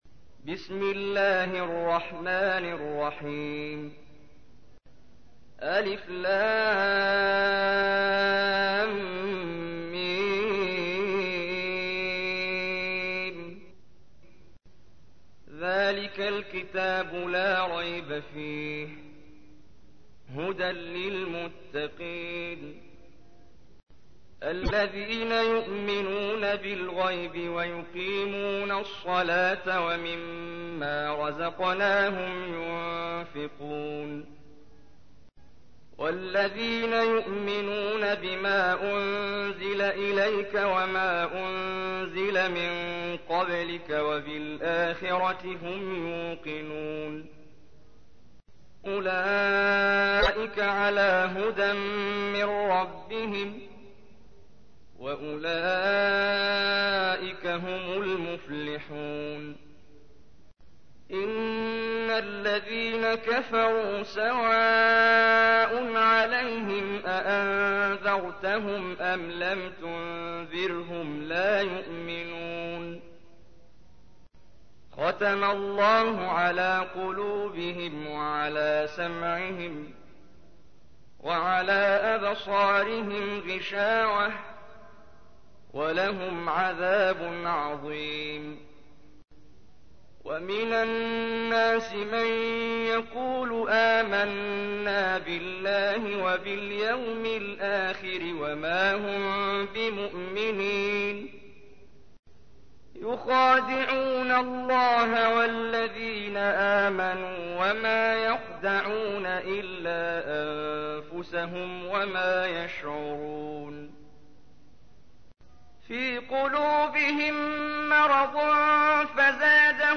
تحميل : 2. سورة البقرة / القارئ محمد جبريل / القرآن الكريم / موقع يا حسين